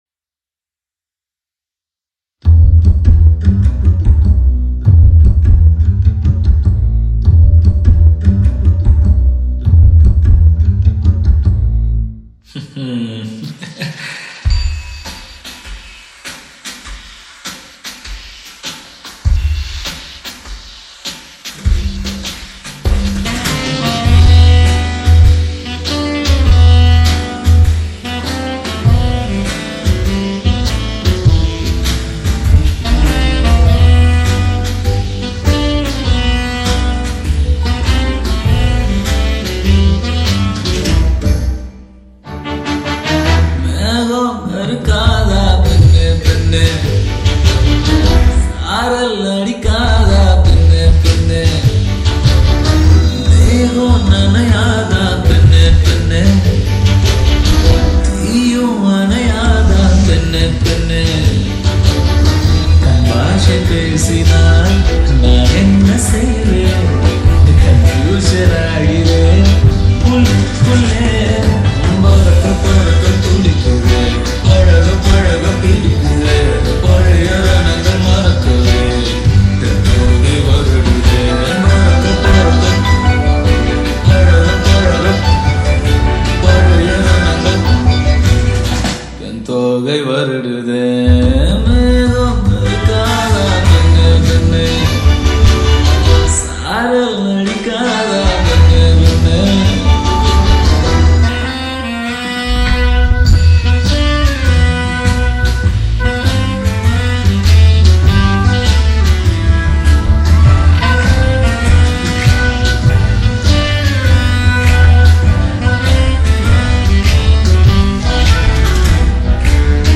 ALL TAMIL DJ REMIX » Tamil 8D Songs